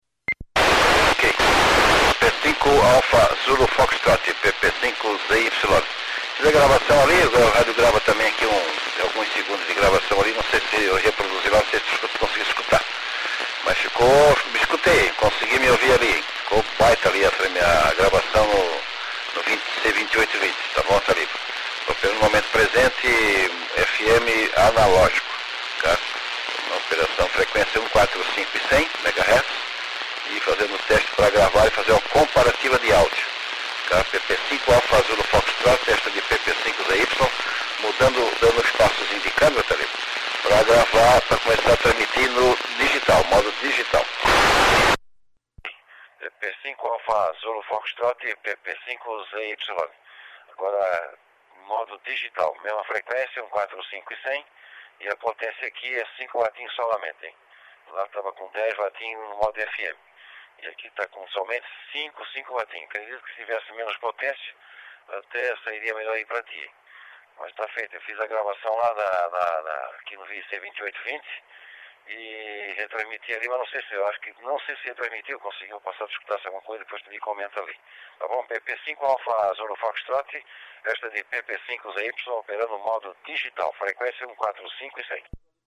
Amostra de áudio analógico (FM) e digital (Dstar) comparativo:
- Mesmo onde o sinal FM chegou sem sinal (busy) e com áudio "sem condições de se entender", o digital decodificou.
- A qualidade do áudio vai piorando a medida que o sinal fica muito fraco, semelhante ao celular.